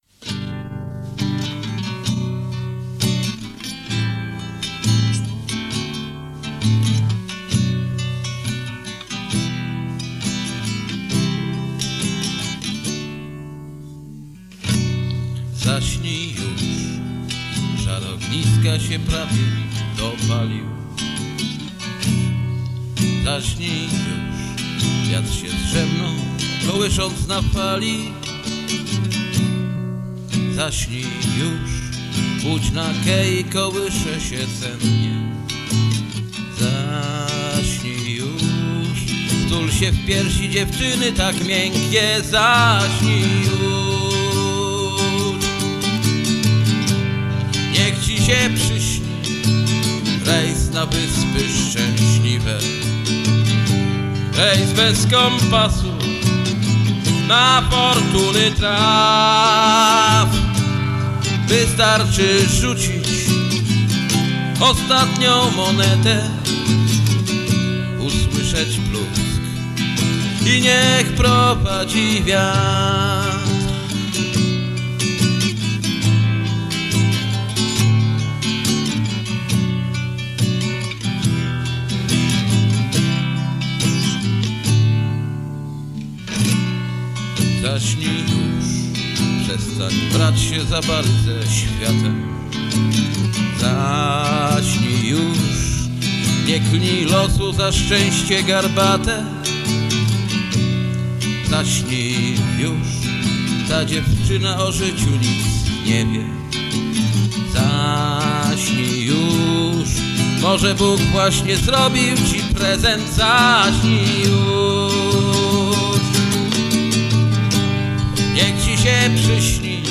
Nagranie z Klubu Oaza wokal, gitara